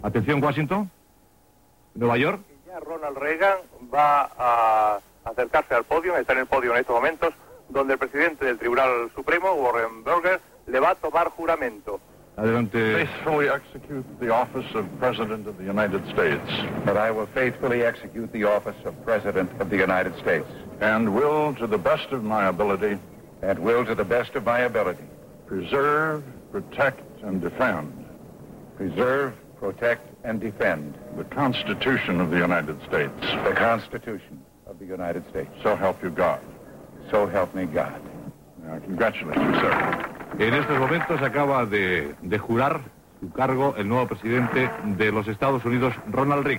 Crònica, des de Nova York, del jurament de Ronald Reagan com a president dels Estats Units de Nord-Amèrica
Informatiu